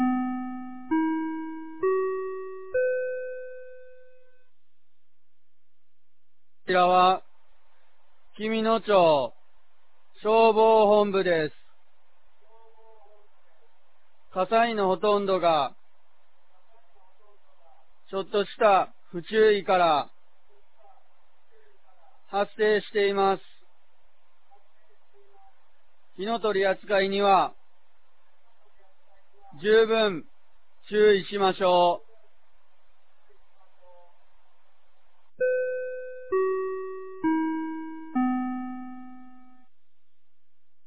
2024年02月03日 16時00分に、紀美野町より全地区へ放送がありました。